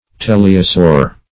Search Result for " teleosaur" : The Collaborative International Dictionary of English v.0.48: Teleosaur \Te`le*o*saur"\, n. (Paleon.)